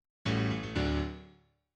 The ending four-note figure in the solo piano part of the finale